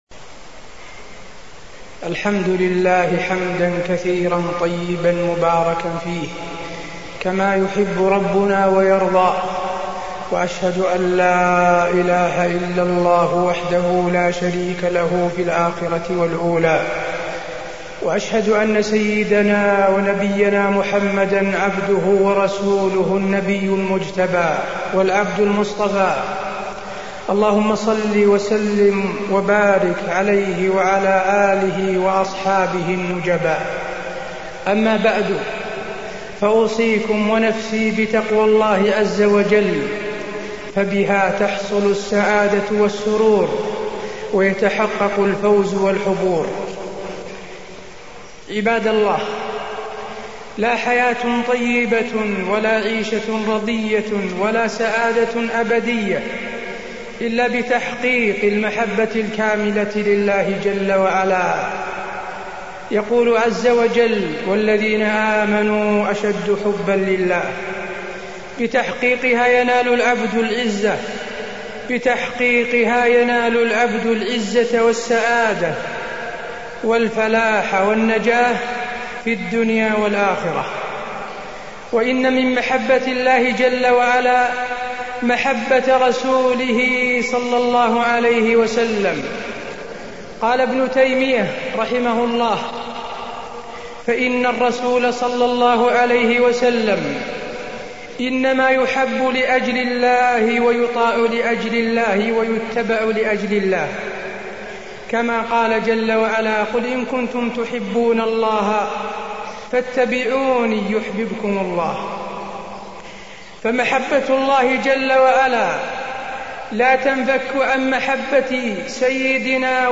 تاريخ النشر ١٨ رجب ١٤٢٥ هـ المكان: المسجد النبوي الشيخ: فضيلة الشيخ د. حسين بن عبدالعزيز آل الشيخ فضيلة الشيخ د. حسين بن عبدالعزيز آل الشيخ محبة الرسول عليه الصلاة والسلام